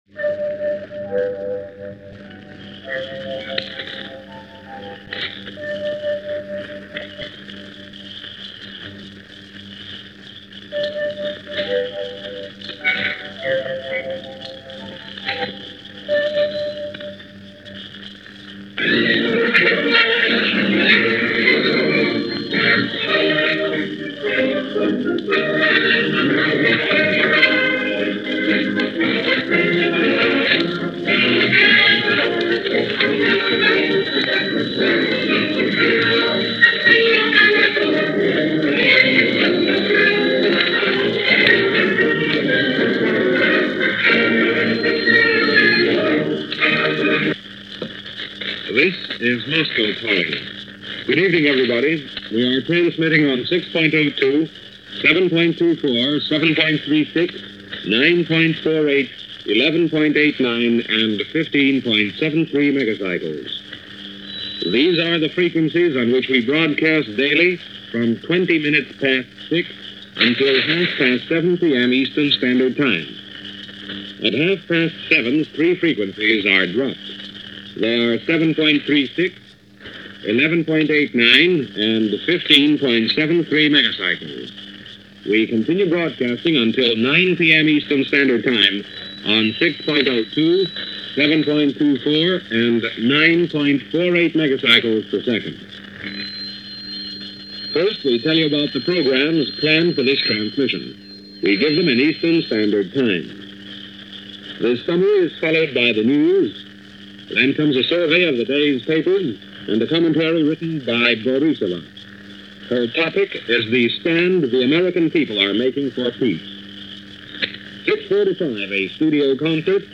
Radio Moscow was a Shortwave station, broadcasting on several frequencies and in several languages, giving the news of the day (from a Russian perspective), the arts, culture and history. Each day Radio Moscow would begin their programs with Moscow Calling, a news and special features service filling listeners in on the goings on, all with a Soviet slant. This particular broadcast comes from March 9, 1947 – Secretary of State George C. Marshall had just arrived in Moscow for talks with Foreign Minister Molotov and covers his arrival and broadcasts his remarks to the Soviet Radio.